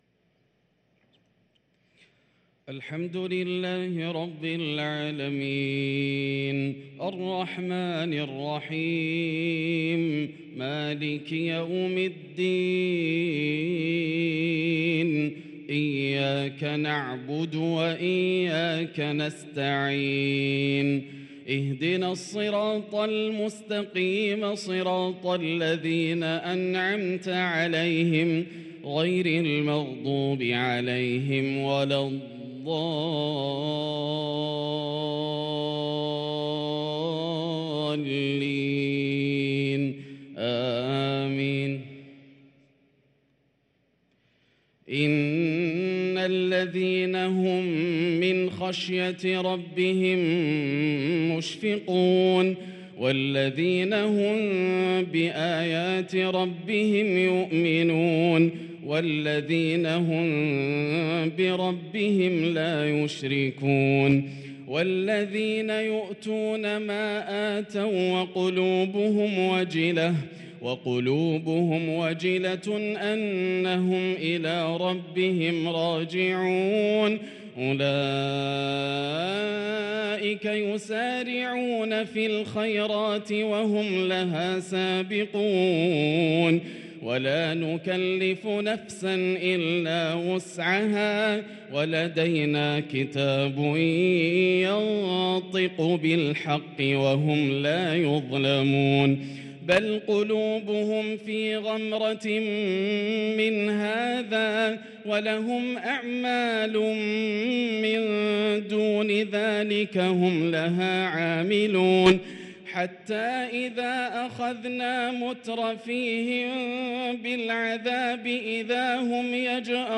صلاة العشاء للقارئ ياسر الدوسري 15 ربيع الأول 1444 هـ
تِلَاوَات الْحَرَمَيْن .